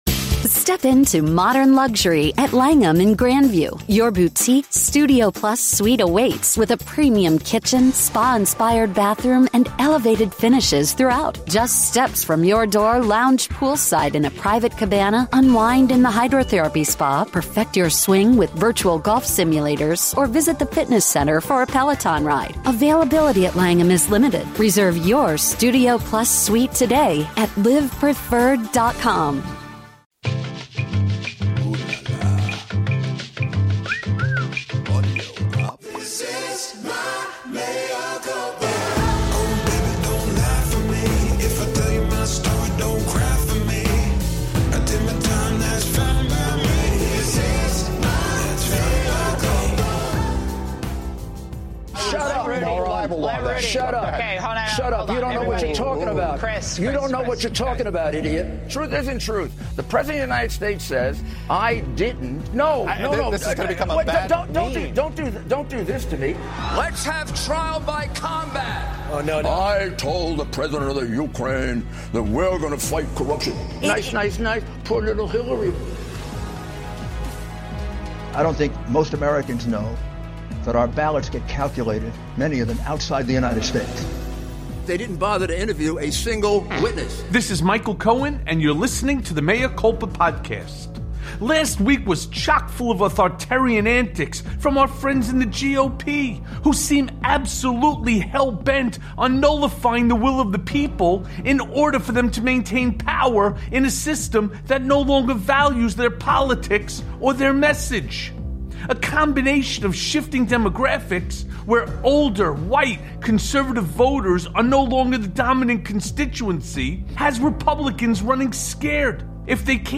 Special Report: The Stunning Fall of Rudy Giuliani + A Conversation